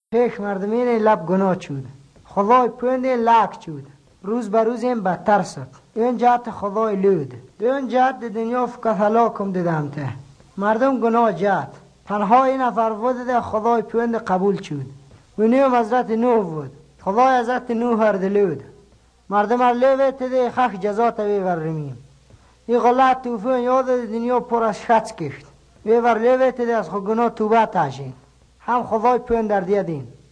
12 December 2016 at 2:22 am I hear the common Iranian words for ‘man’ (mard) and ‘day’ (rūz), and the recognizable low vowel distinction between an [æ]-like vowel and a rounded backer vowel. At the same time, I hear front-ish rounded vowels much like the Turkic languages.